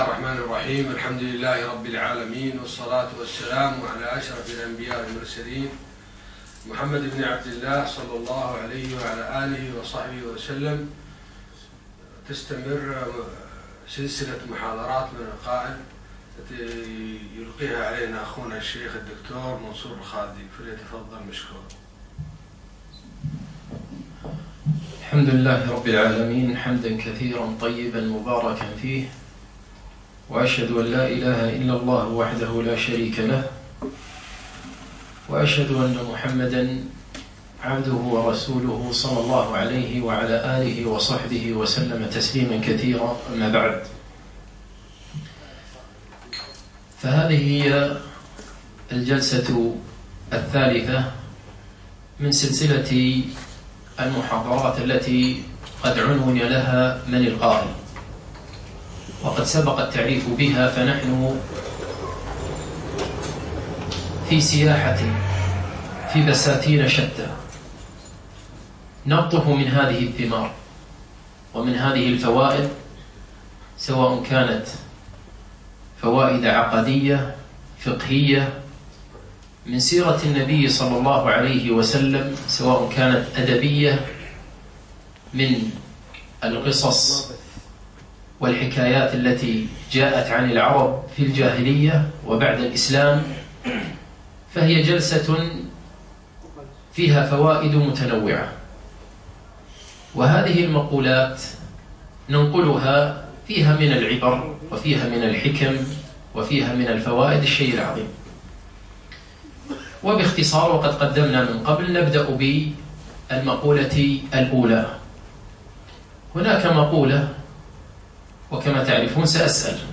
3- من القائل ؟ - المحاضرة الثالثة